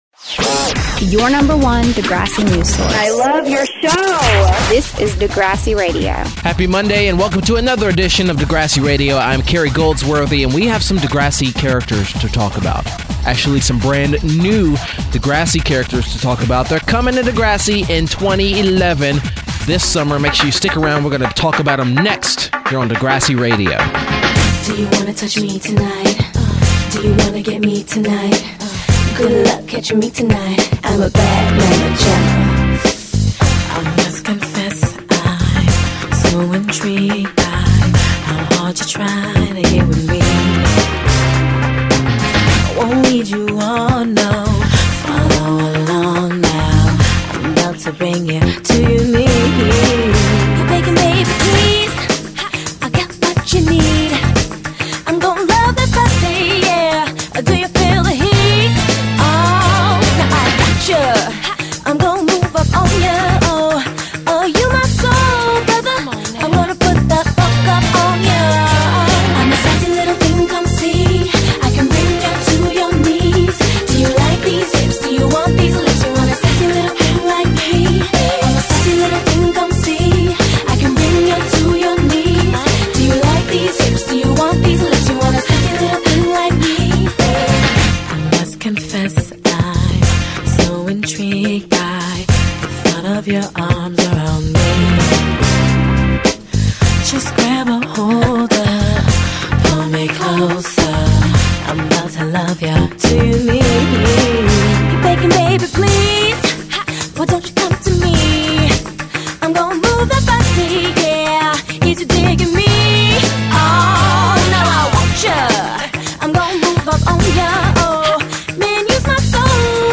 So the entire time I kept pronouncing it ImoJEAN instead of ImoGEN.